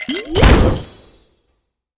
potions_mixing_alchemy_05.wav